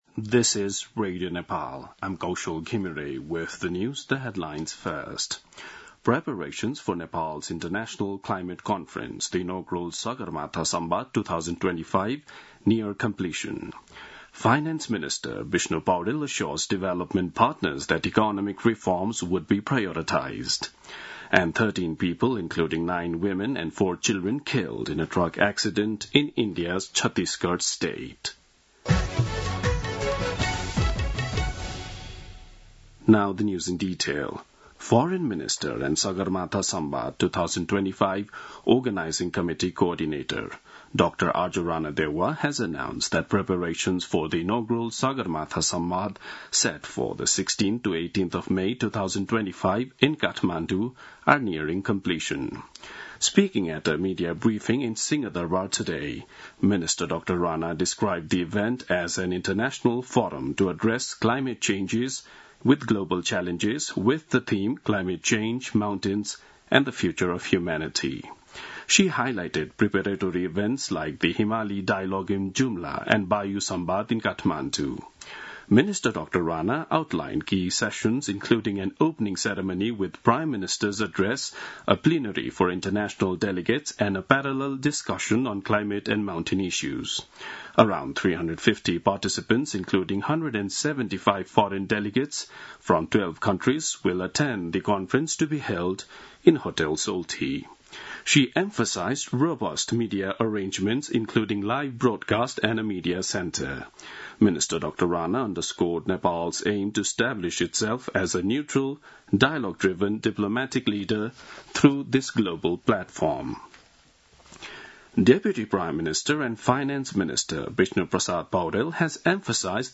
दिउँसो २ बजेको अङ्ग्रेजी समाचार : ३० वैशाख , २०८२